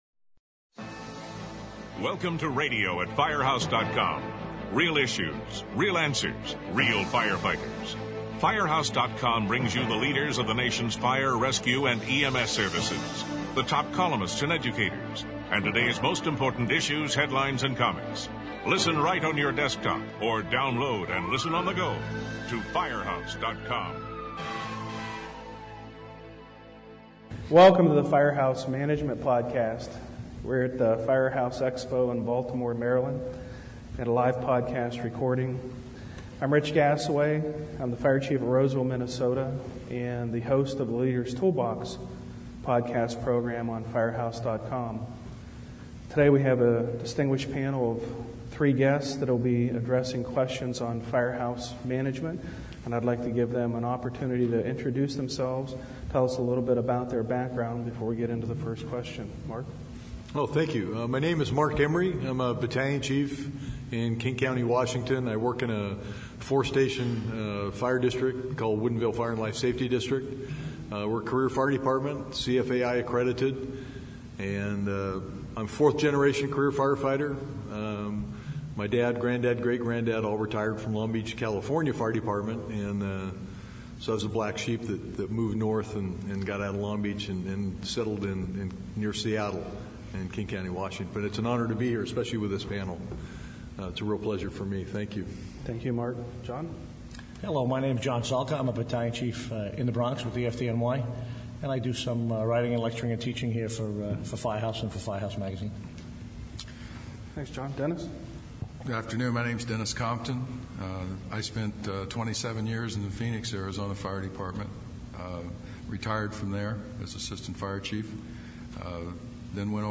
In this podcast, fire chiefs from around the country tackle a myriad of management issues that can be found in any fire station. This roundtable discussion took place at Firehouse Expo. Tune in to hear what they have to say about keeping firefighters informed of ever-changing standards. The chiefs also discuss the importance of providing a positive team approach.